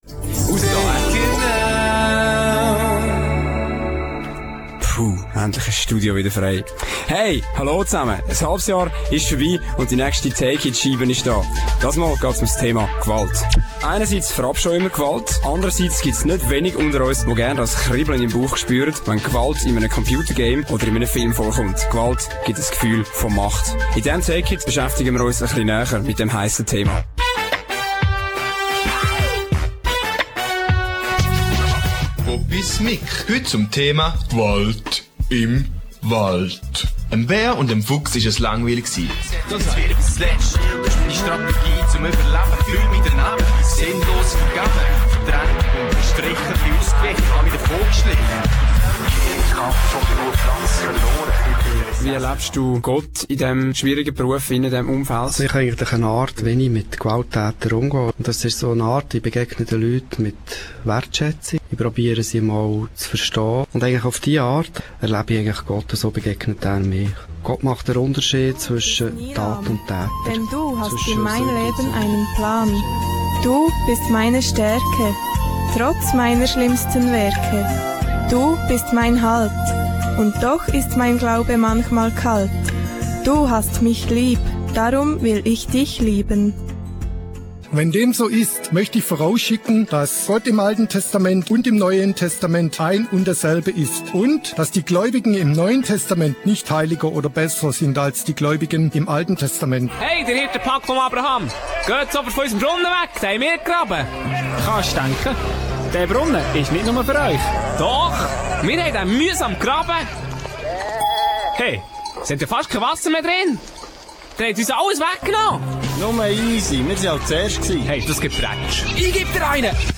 Take it! // Das coole schweizerdeutsche Hörmagazin für Teens
Take it!-Trailer für Realmediaplayer (728 KB)